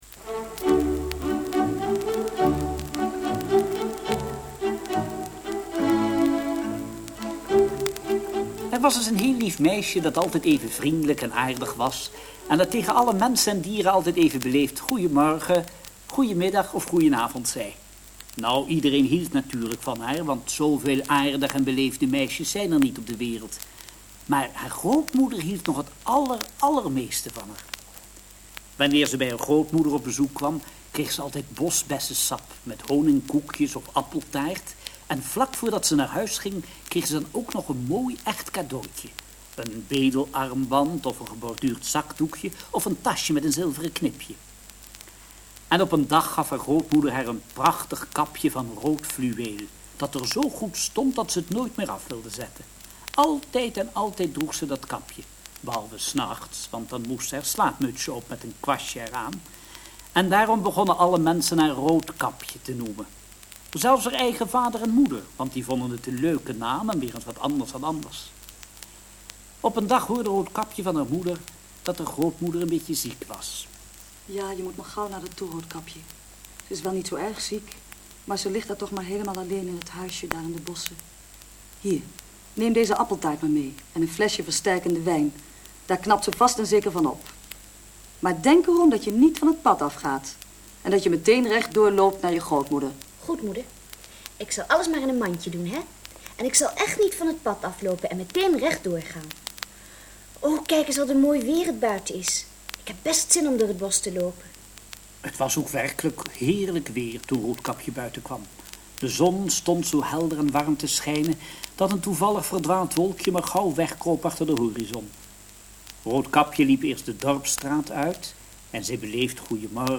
Hoorspel bewerkt door Hans Andreus